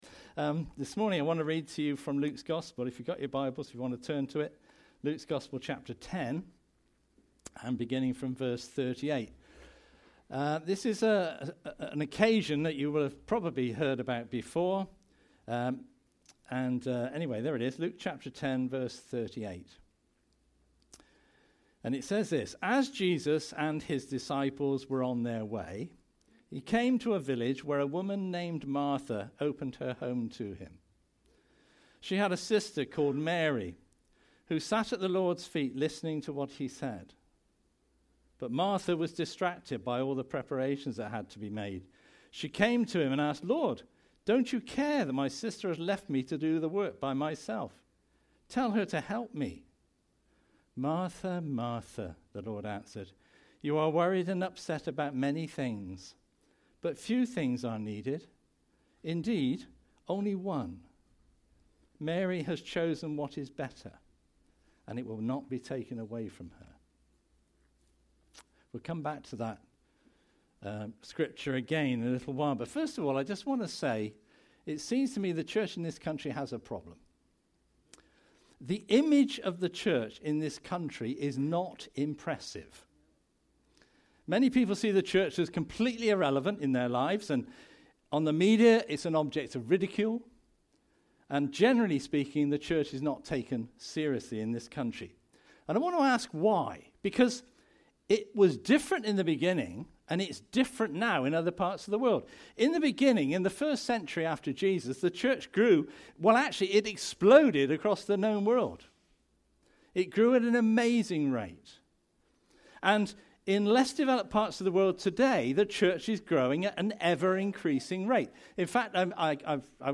preach